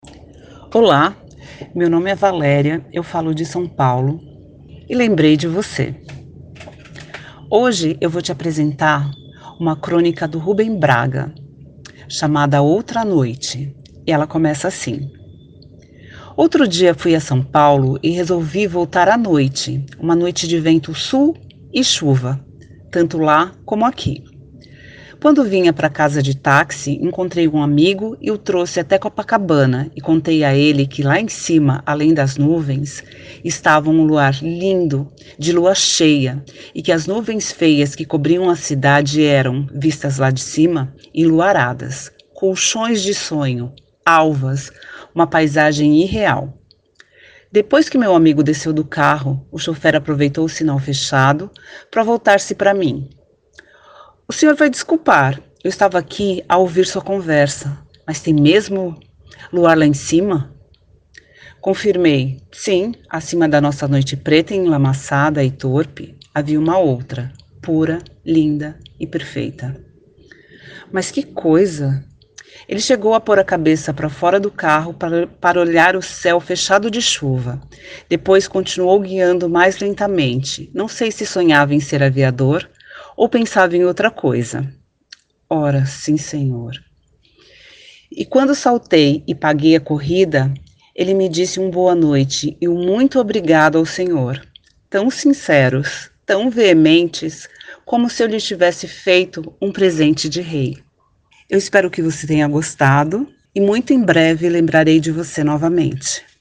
Crônica Português